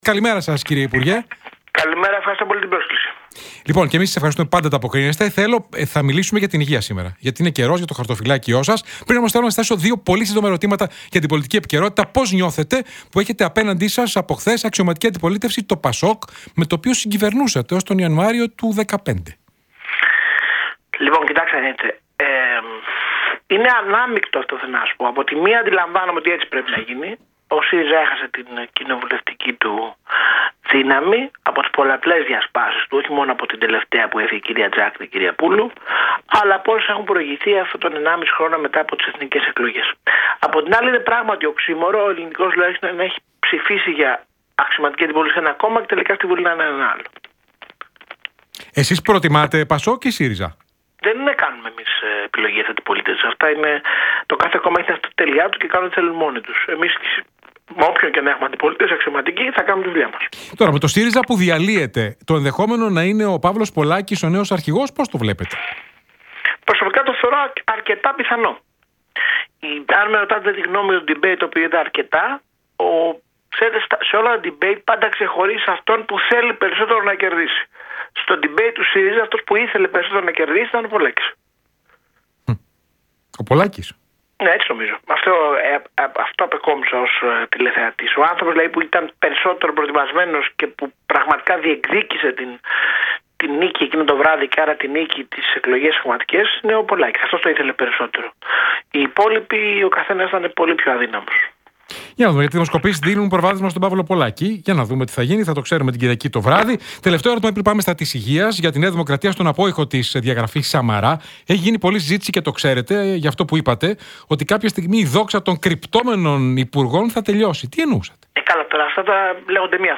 Τι δήλωσε ο υπουργός Υγείας στον realfm 97,8.